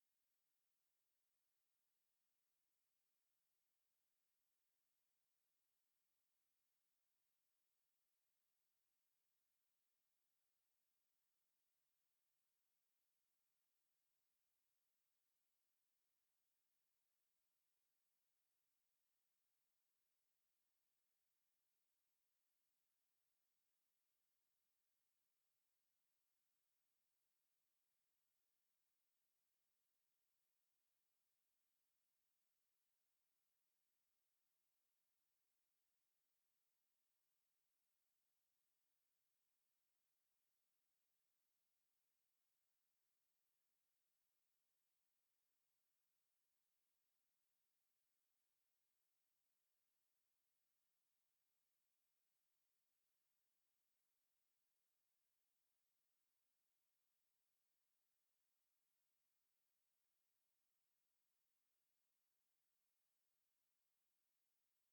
Auf dem Akko Vögel, Hunde, Tiere, Gegenstände oder Geräte imitieren...
Nachdem sich hier die Welt Elite des Akkordeonspiels die Türklinke in die Hand gibt und ihre Tierstimmen und Geräuschinterpretationen vorstellt, habe ich es mir natürlich nicht nehmen lassen und auch mal ein Tier mit dem Akkordeon imitiert.